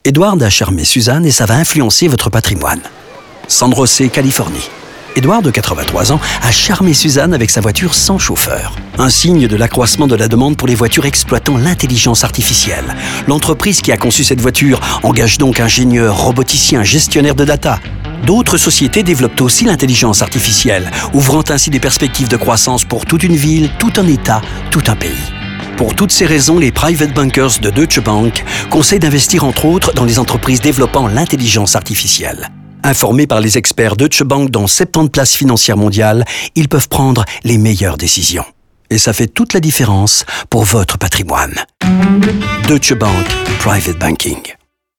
Nous prenons également le temps de développer les histoires de Rinku, Søren, Juan et Edward dans des spots radio de 45 secondes.